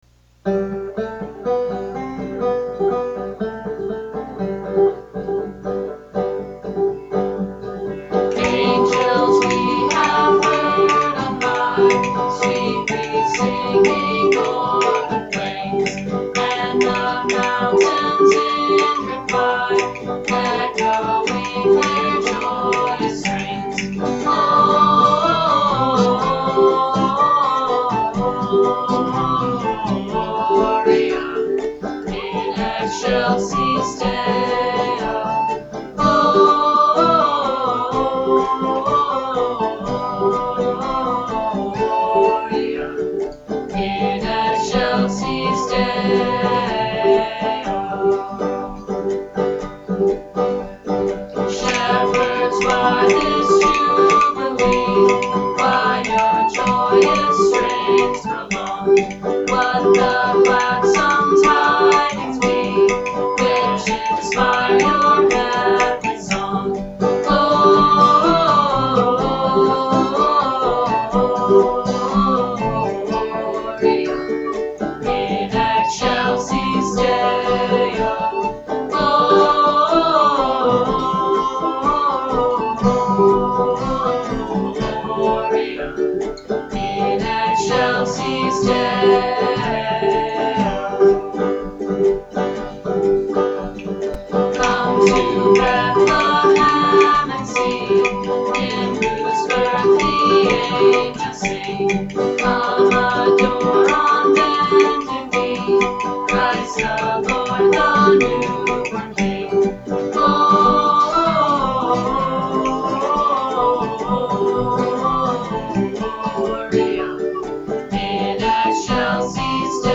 banjo and vocals
vocals and bones
guitar
tin whistle
ukulele and vocals